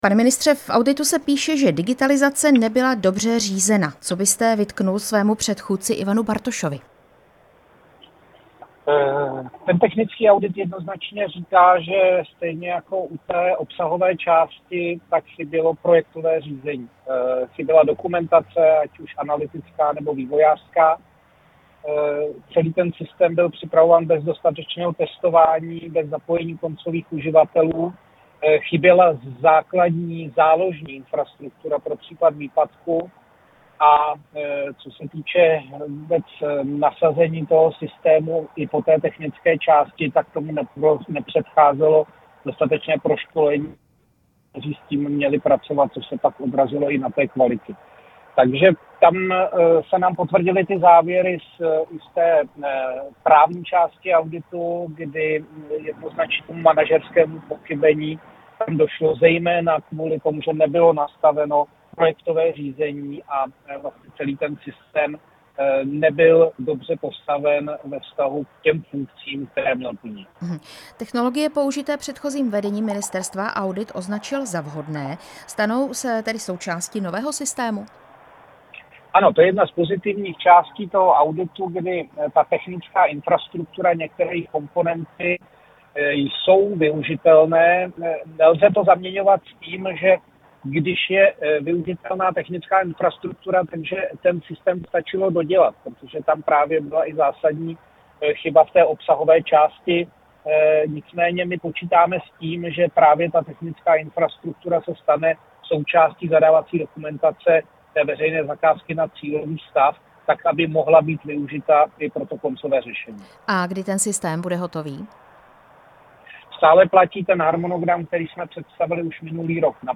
Rozhovor s ministrem pro místní rozvoj Petrem Kulhánkem